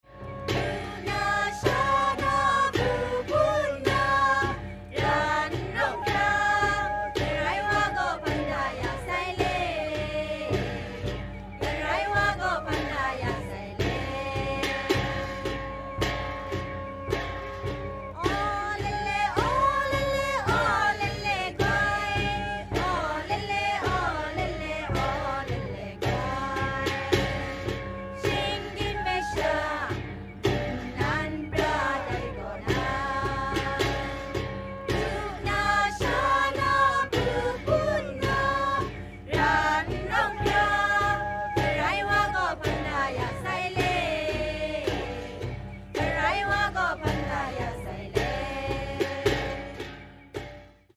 Kachin music
choral song: My beautiful country the musicians rehearse for the festival and sing about the beautiful country they have left accompanied by percussion 797KB